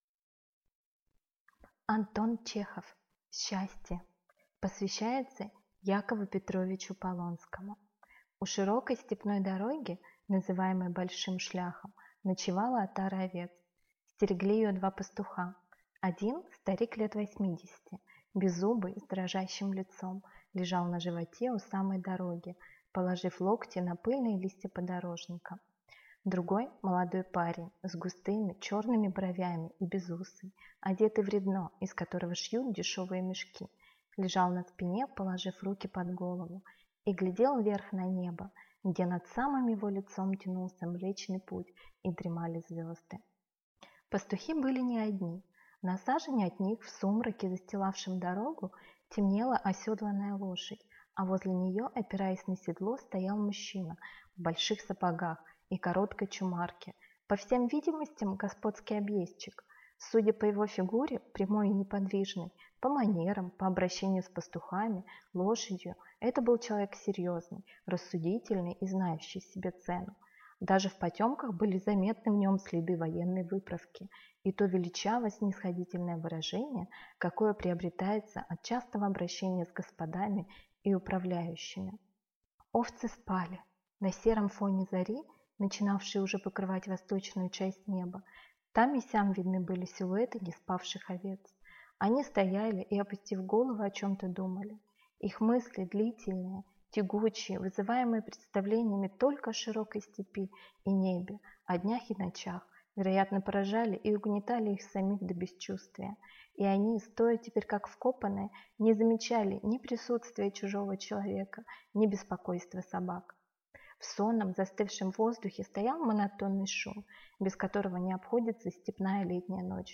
Аудиокнига Счастье | Библиотека аудиокниг